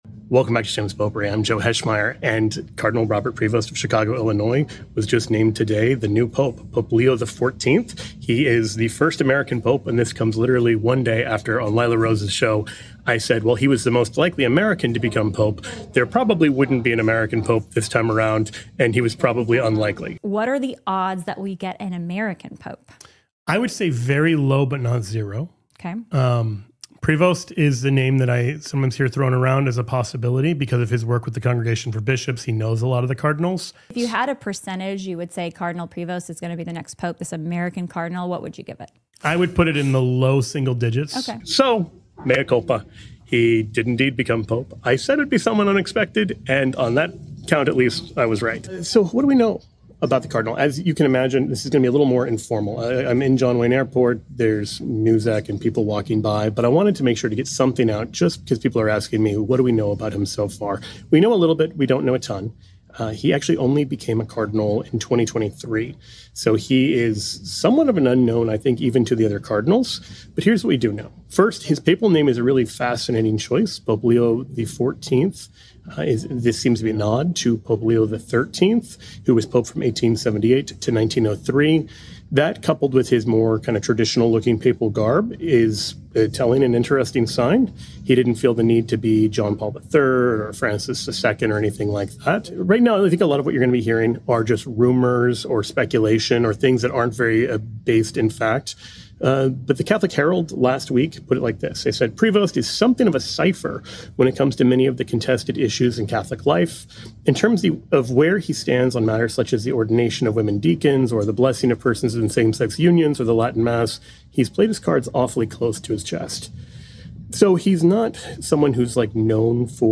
As you can imagine, this is going to be a little more informal.